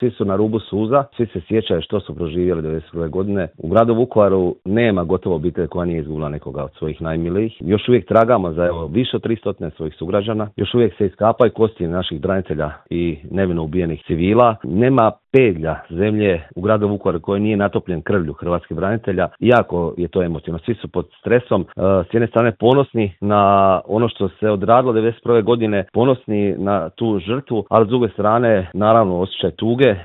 Vukovarski gradonačelnik Marijan Pavliček u Intervjuu Media servisa kaže da je grad već danima pun hodočasnika: